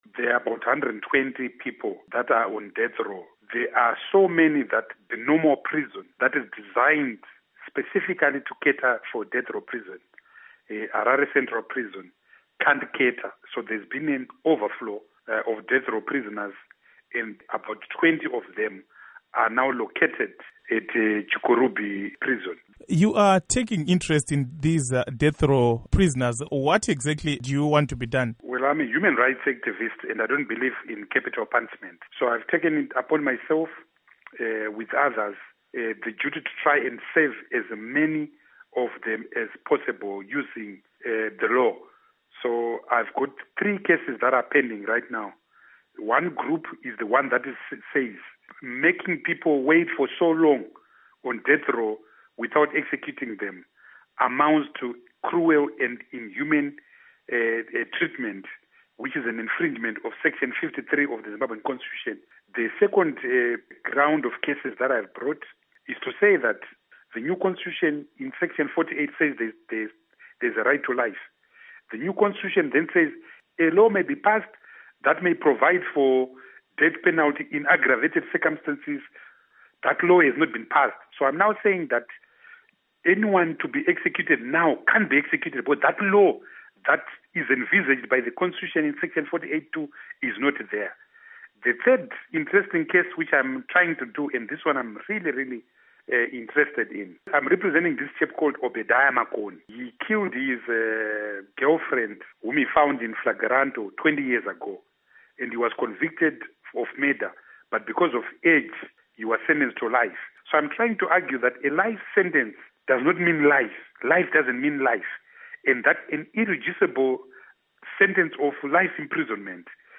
Interview With Tendai Biti on Death Penalty